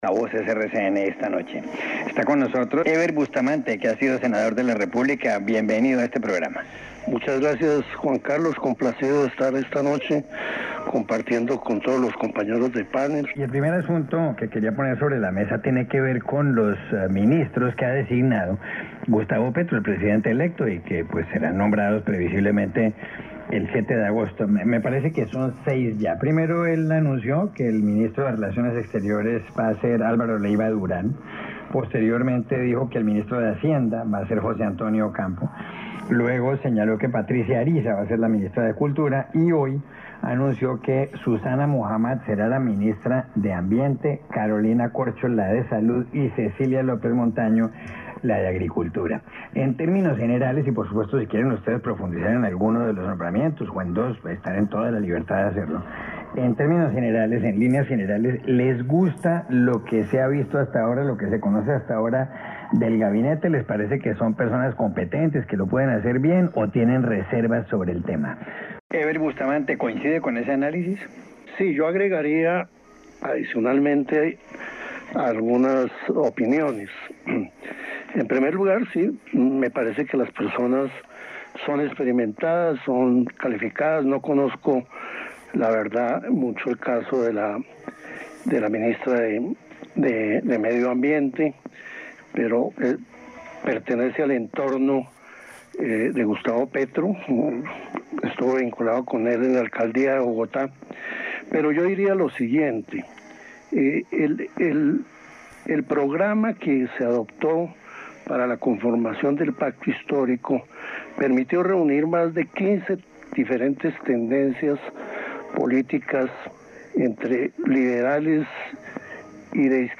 Everth Bustamante habló en Voces RCN, sobre los primeros movimientos del nuevo Gobierno. 06 de julio de 2022